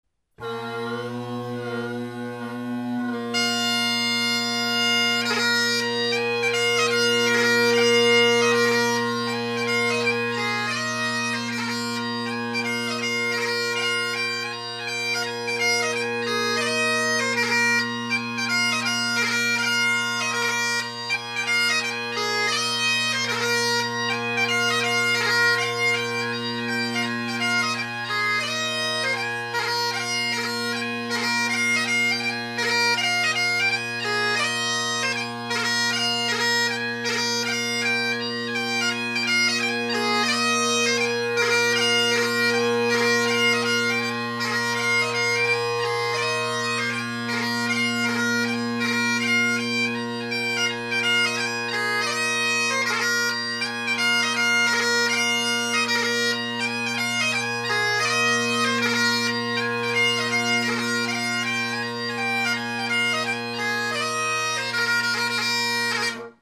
Great Highland Bagpipe Solo, Reviews
Although the MacLellan reed is now kinda bleh, I did notice when reeding the Kyo that I had to pull the reed out quite a bit and the pitch ultimately settled around 472 Hz.
Colin Kyo – MacLellan reed – Delvinside – laminate chanter